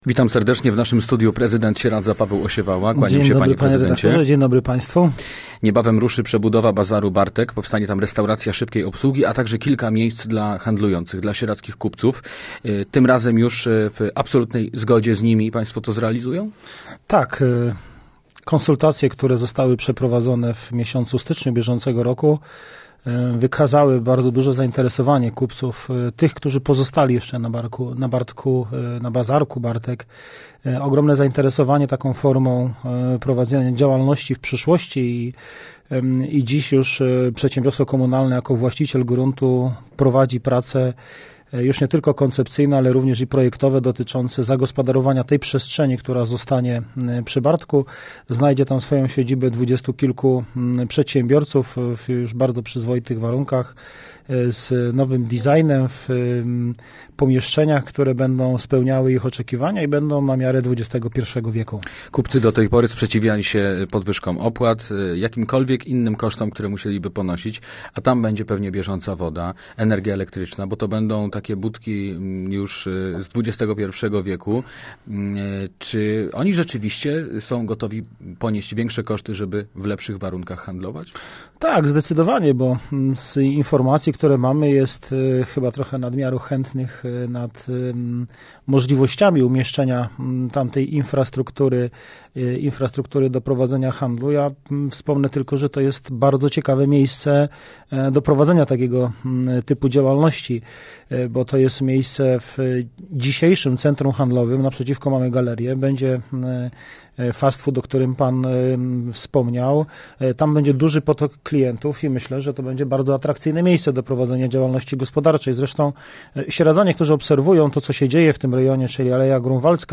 Gościem Radia Łódź Nad Wartą był prezydent Sieradza, Paweł Osiewała.